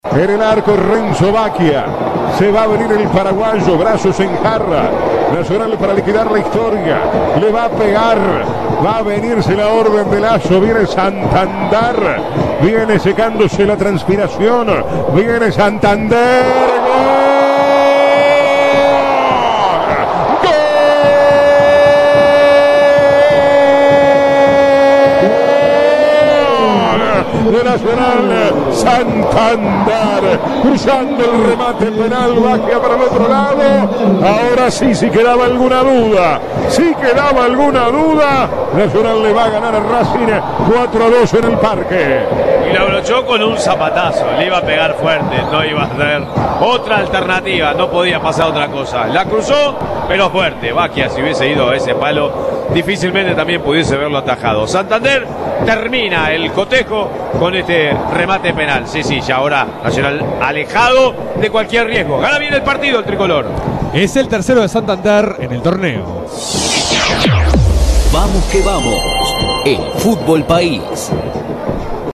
La goleada tricolor en la voz del equipo de Vamos que Vamos